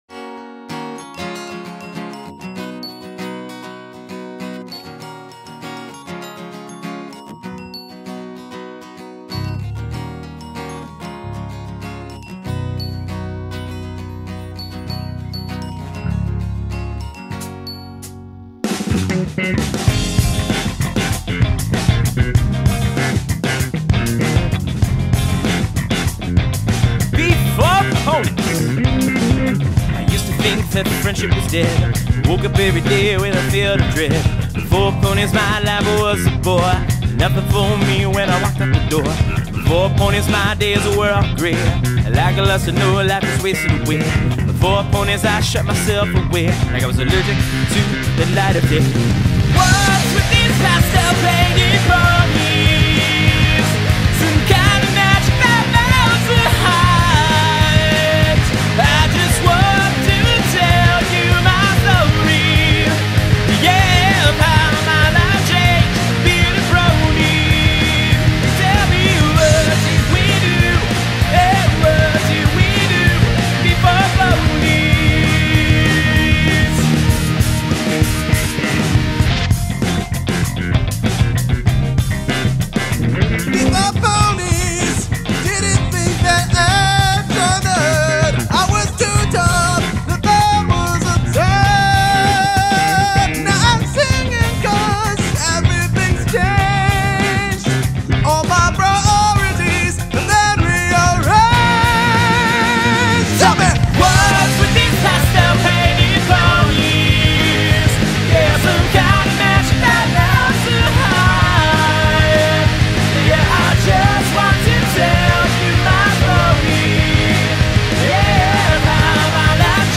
Bass Guitar
Guitar Solo